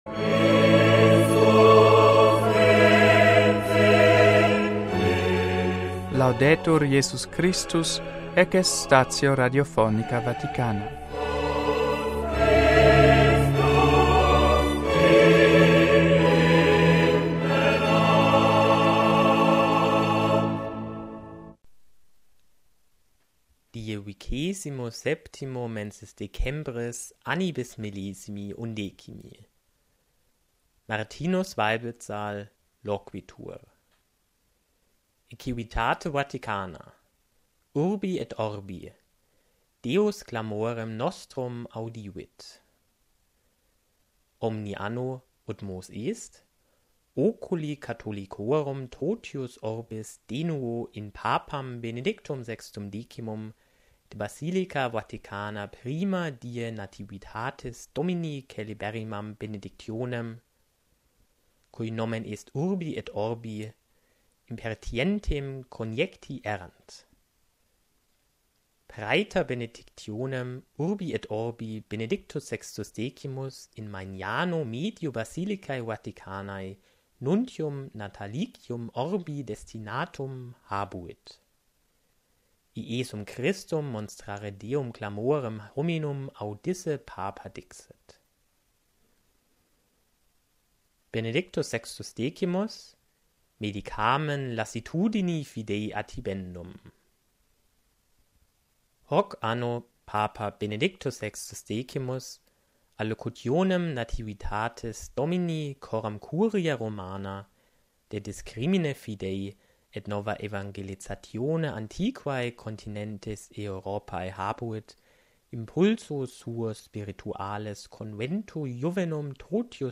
NUNTII STATIONIS RADIOPHONICAE VATICANAE PARTITIONIS GERMANICAE